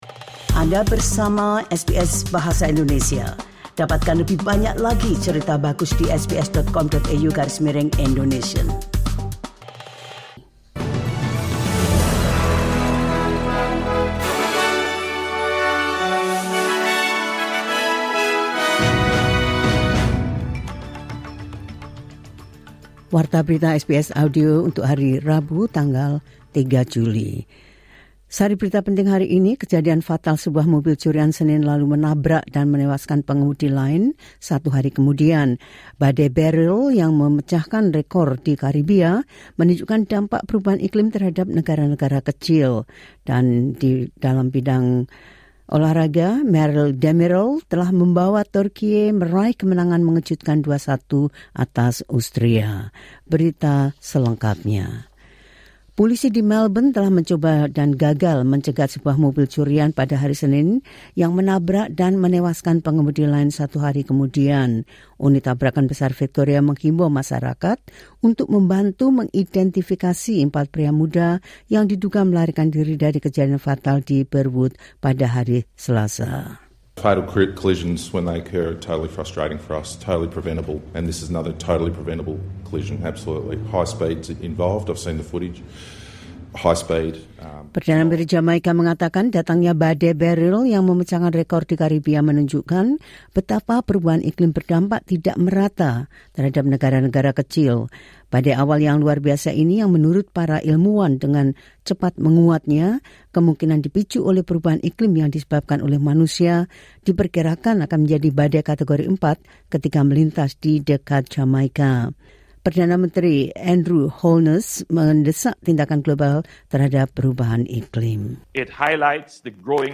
Berita terkini SBS Audio Program Bahasa Indonesia – 03 Jul 2024
The latest news of SBS Audio Indonesian program – 03 Jul 2024.